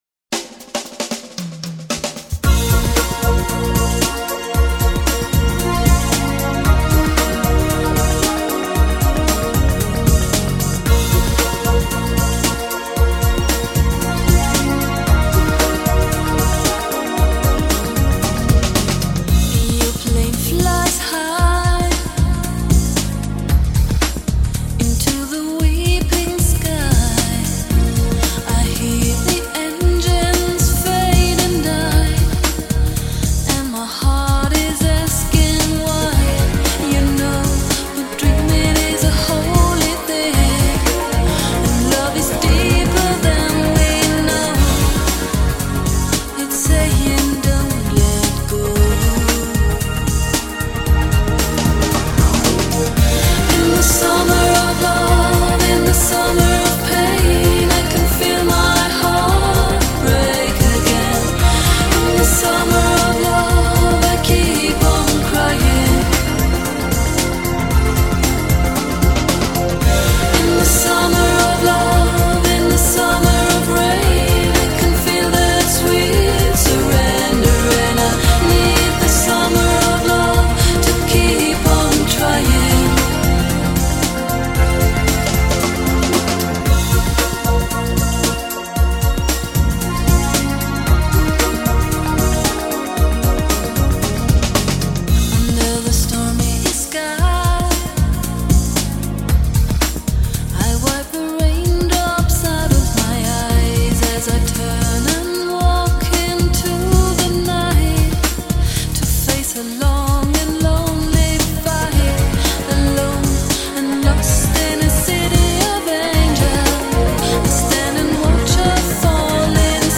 Спасибки , музычка при общем немного быстрая ....но по тексту и мелодичности её настроению подходит на мой взгляд.......